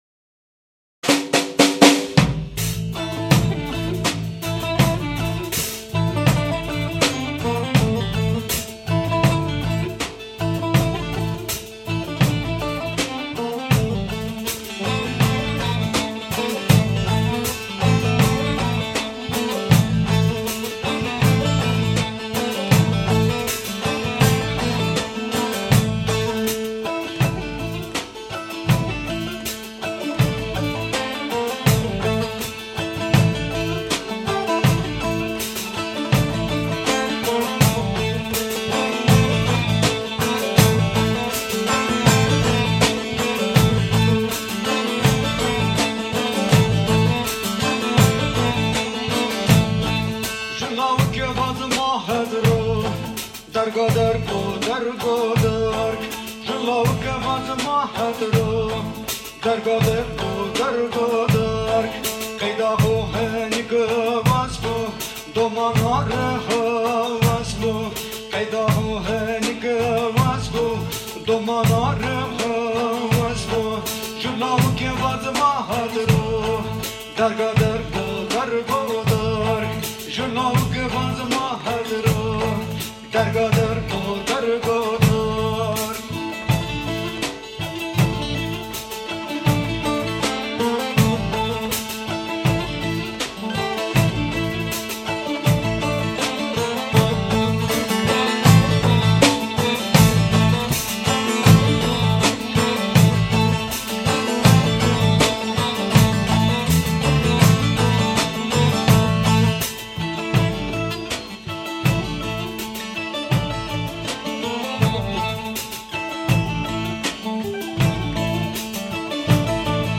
Zazakisch-kurdische Weltmusik aus dem Osten Anatoliens.
Tembur, Saxofon, Gesang
Tembur, Erbane, Gesang
Duduk, Zirne
Fretless-Bass
Schlagzeug, Perkussion